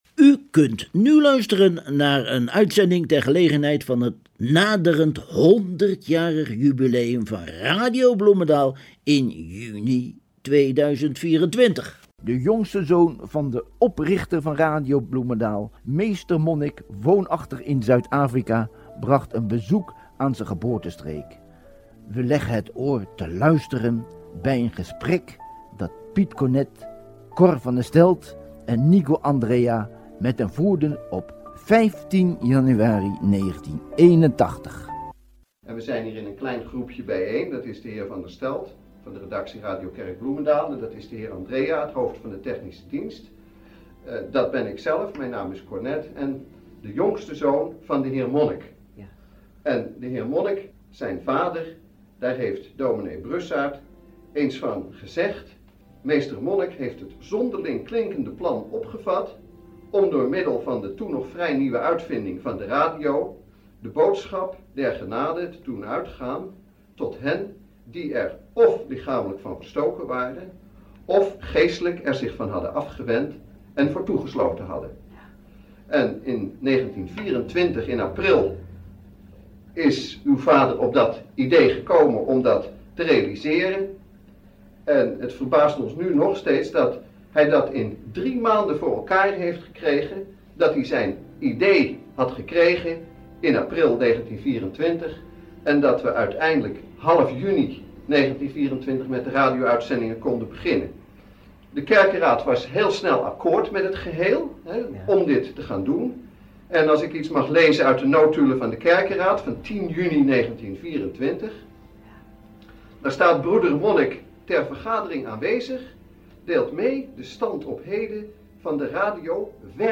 Er is veel bewaard gebleven aan programma’s uit de bijna 100 jaren die Radio Bloemendaal bestaat.
Vervolgens een aan- en afkondiging uit de 30-er jaren.